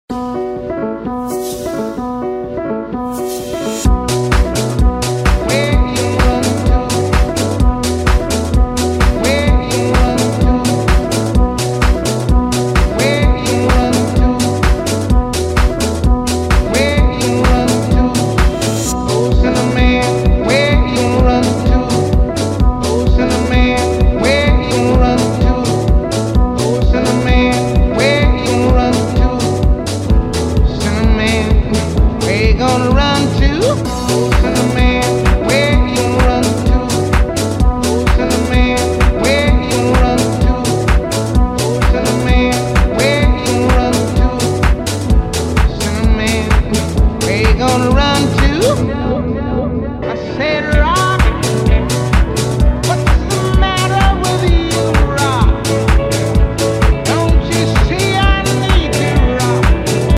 Mp3 Sound Effect
traxxas trx4 defender pulls our my element enduro trailrunner with injora winch.